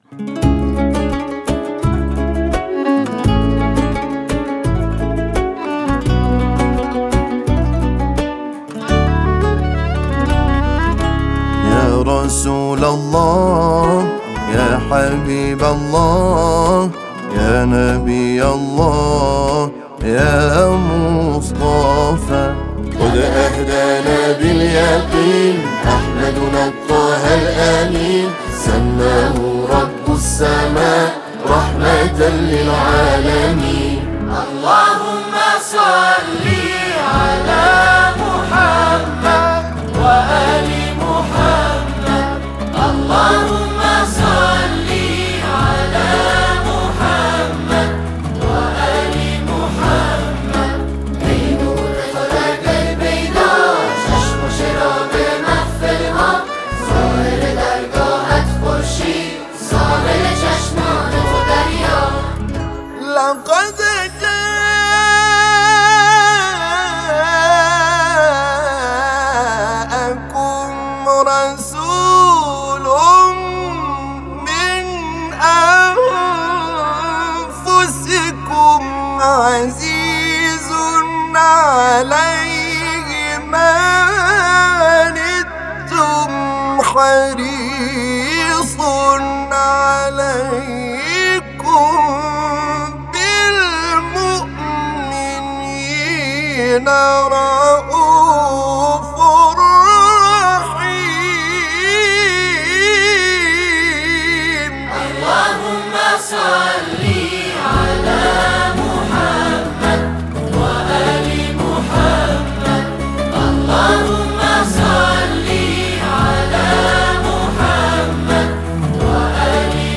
اثری دل‌انگیز و معنوی